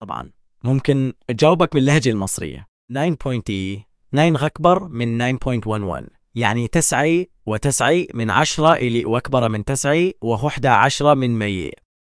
وللتحقق من إمكانية تحدثه بلهجات عربية أخرى استأنفت معه الحديث، ولكن هذه المرة مستخدمًا صوتي، طالبًا إياه التحدث باللهجة المصرية.
أجابني نعم سأحدثك بها، لكن لم يكن هناك فارق مع لهجته السابقة.